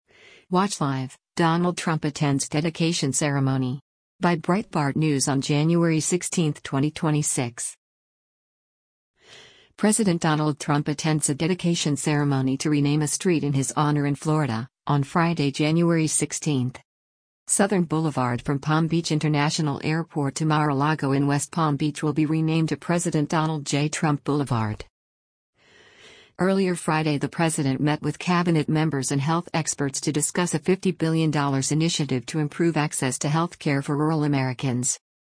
President Donald Trump attends a dedication ceremony to rename a street in his honor in Florida, on Friday, January 16.